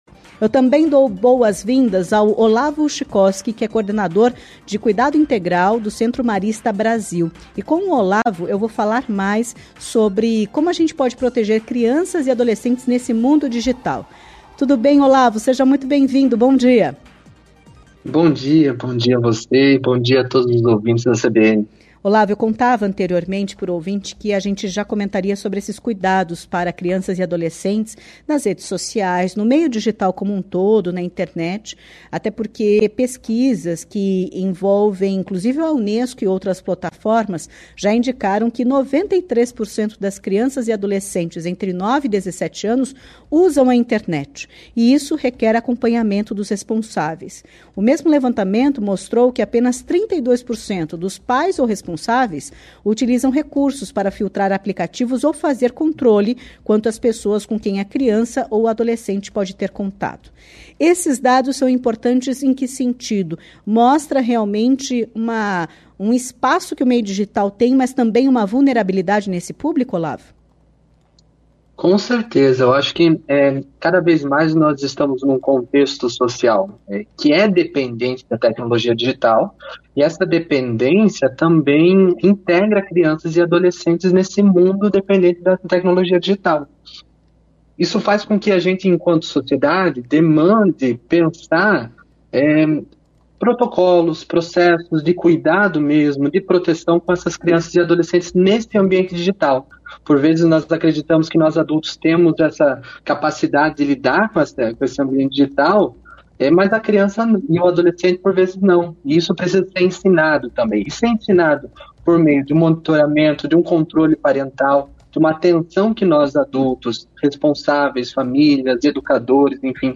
deixou dicas para proteger crianças e adolescentes na internet durante entrevista à CBN Curitiba nesta quinta-feira